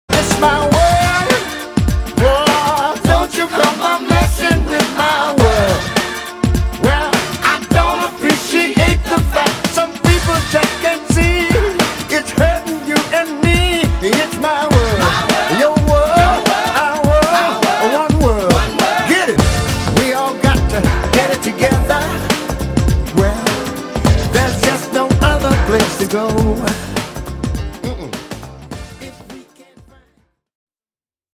Gravação e Programação de Synths e Bateria/Percussão